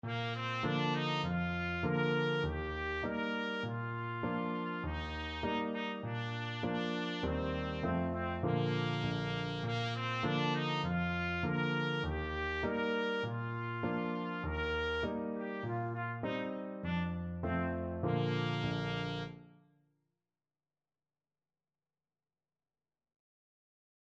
Moderato
4/4 (View more 4/4 Music)